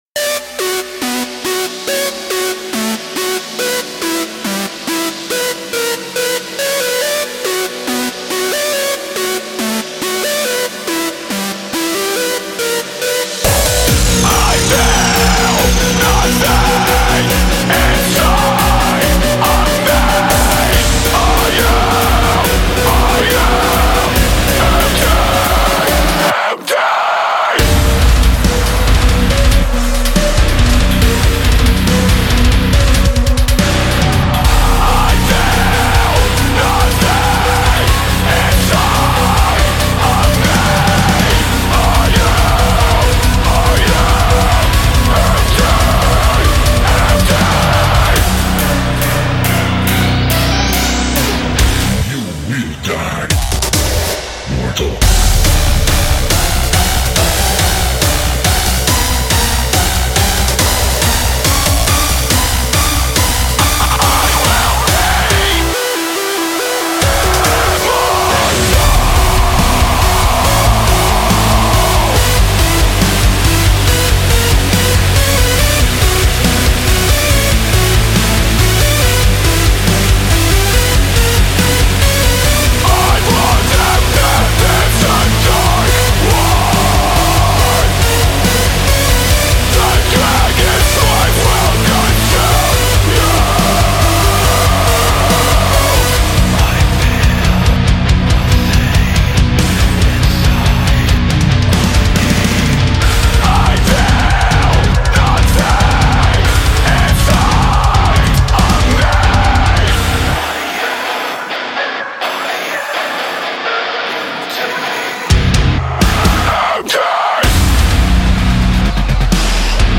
BPM70-140
Audio QualityPerfect (Low Quality)